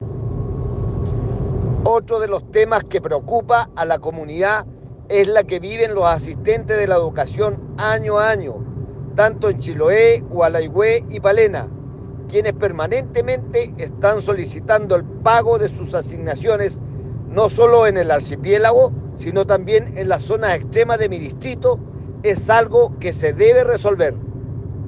Por otra parte, el parlamentario se refirió a otro de los temas que preocupa a la comunidad: